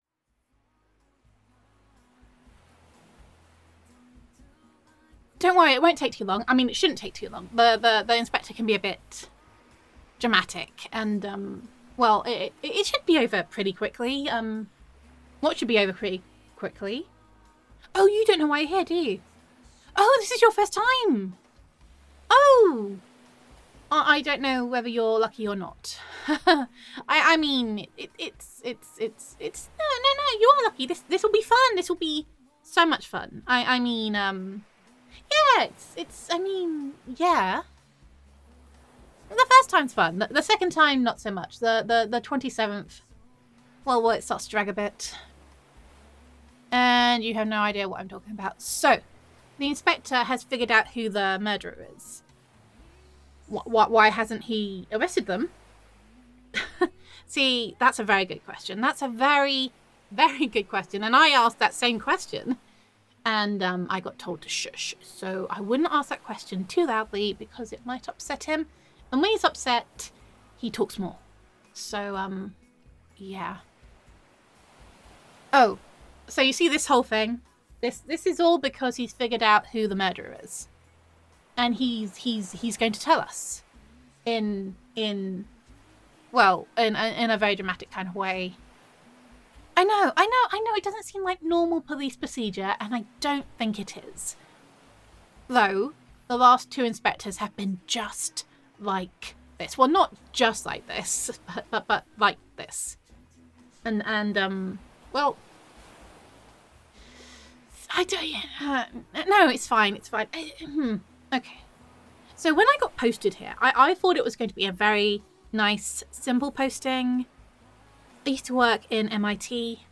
[F4A] Lovestruck in a Daytime Murder Mystery [Detective Roleplay]